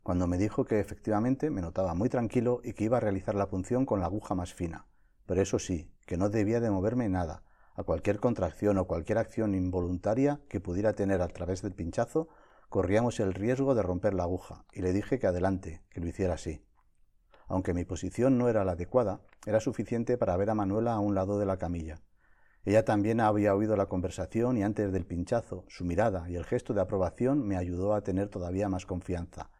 Audiolibro
Extracto capítulo "Mi nueva compañera de viaje", página 173